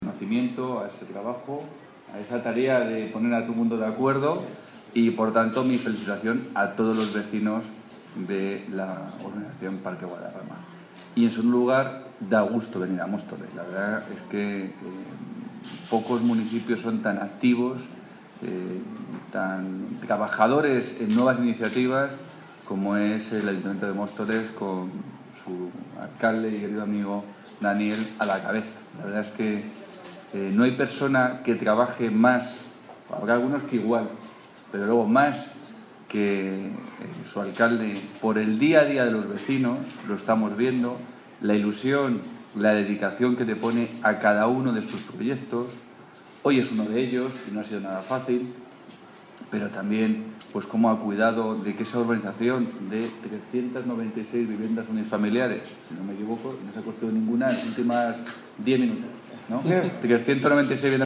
Audio - Salvador Victoria (Consejero de Presidencia y Portavoz del Gobierno regional) Sobre Firma Convenio